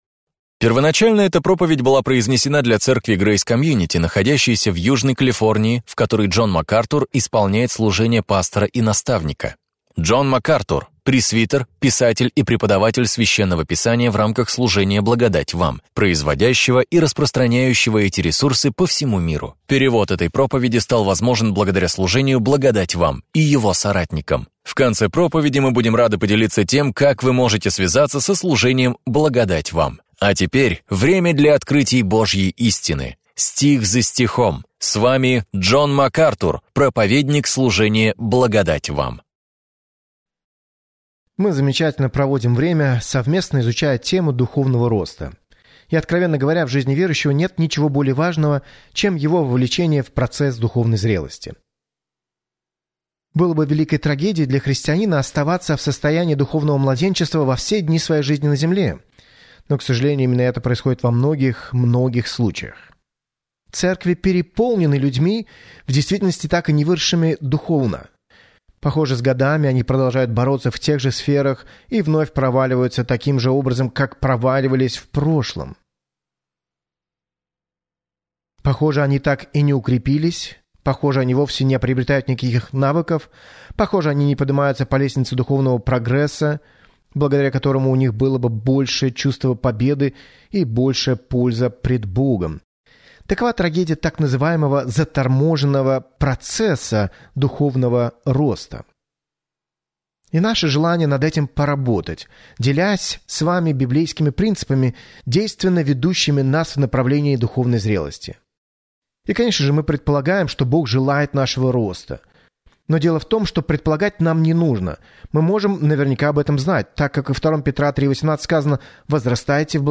В своей проповеди «Возвращение к основам», актуальной для всякого времени, Джон Макартур делает обзор базовых принципов христианства и помогает вам сделать их основанием для своей жизни.